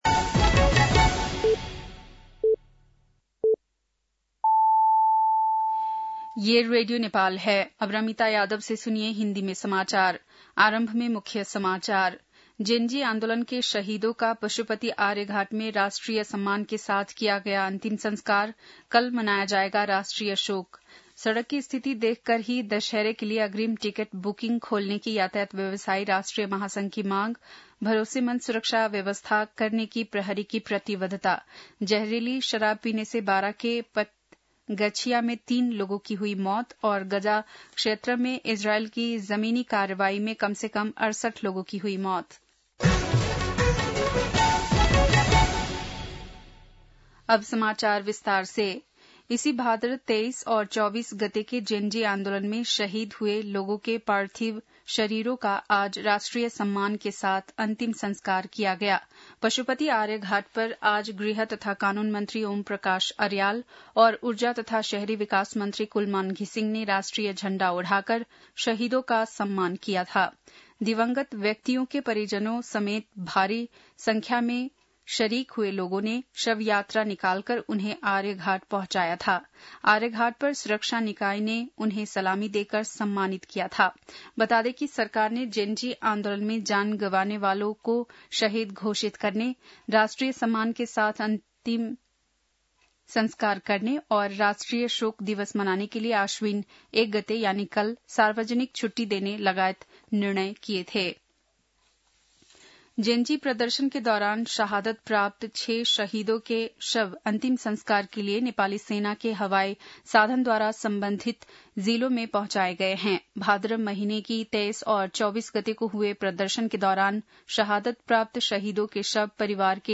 बेलुकी १० बजेको हिन्दी समाचार : ३१ भदौ , २०८२
10-pm-hindi-news-5-31.mp3